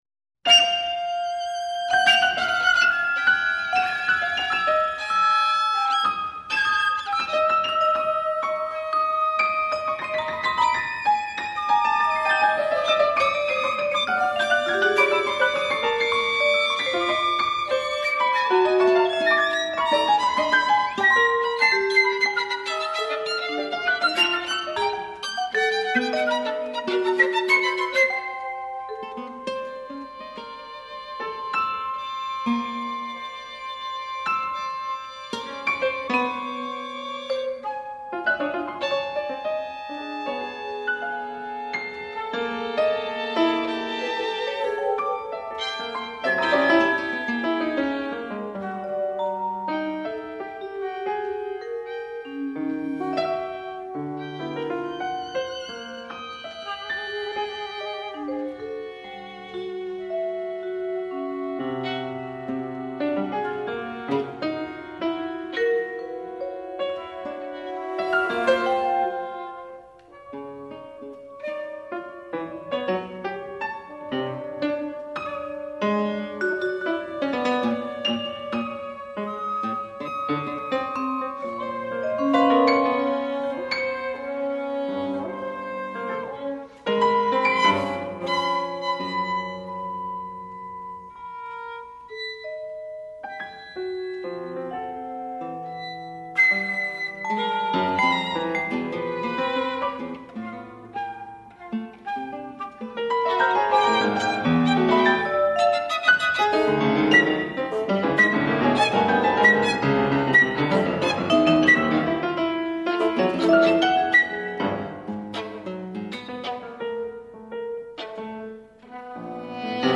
sextet
for flute, bassoon, guitar, violin, vibraphone and piano.